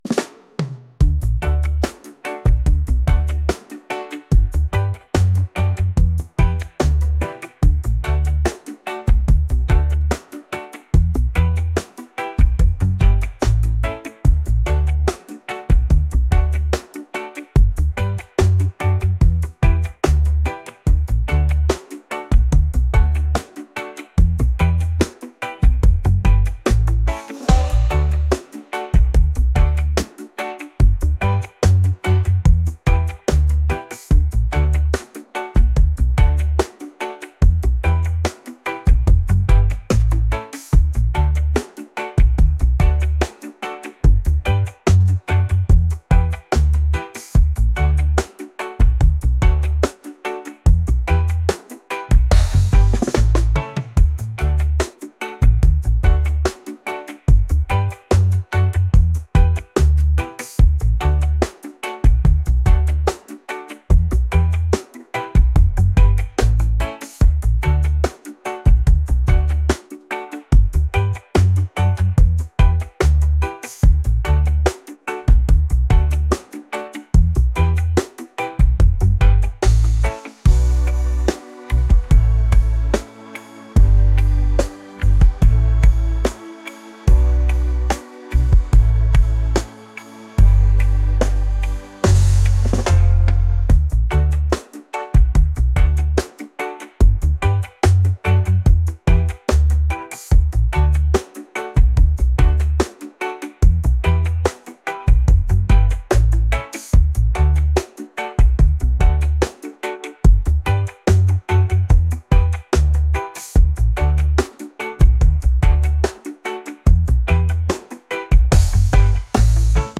reggae | world | soul & rnb